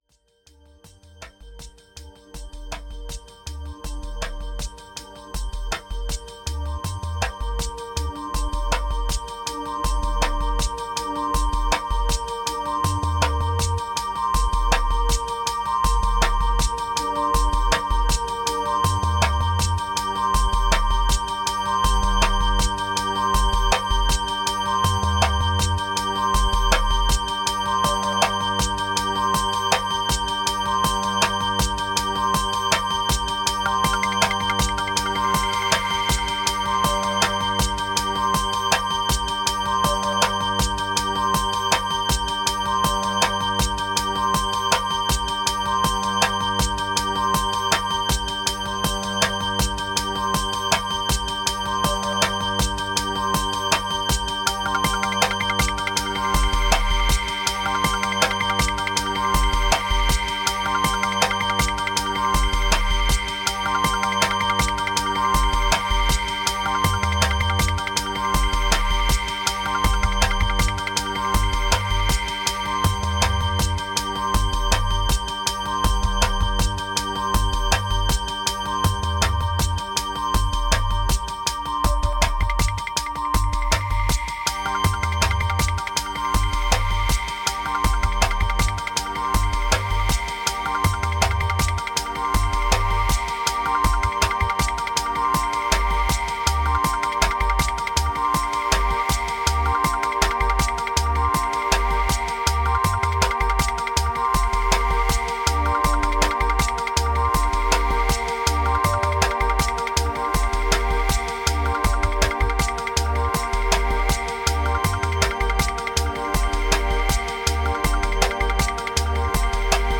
Riddim Relief Deviation Love Failures Pattern Dub Apa